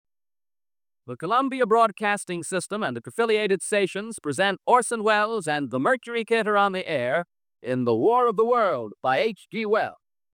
Orson Welles - War Of The Worlds (Production ready)